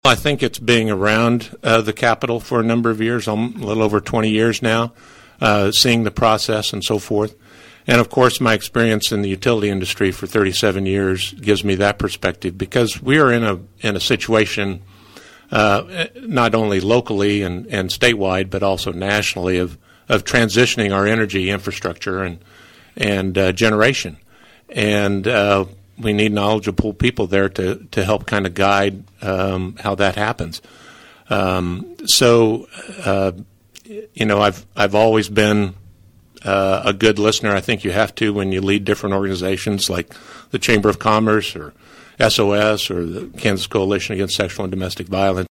The candidates for the Kansas House 60th District position clashed on several topics during KVOE’s final Candidate Forum of this general election cycle.